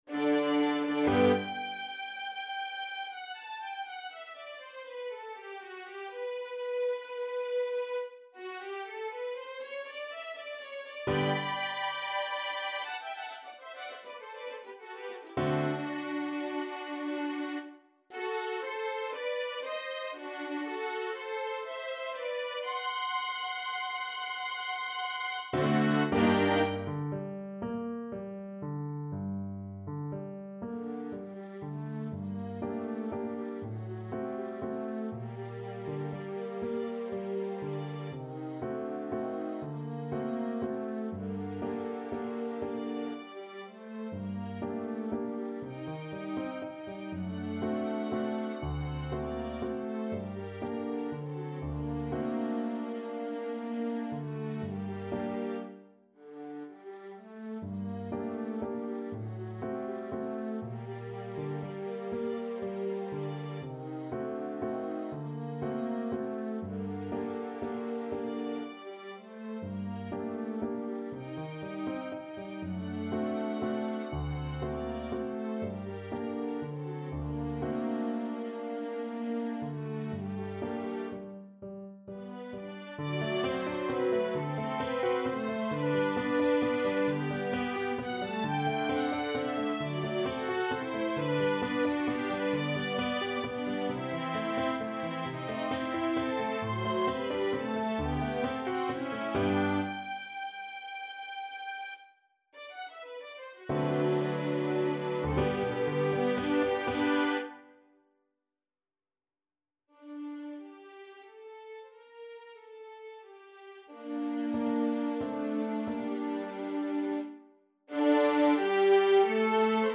タイトルをクリックすると，楽譜と楽譜ソフト再生音源を参照できます．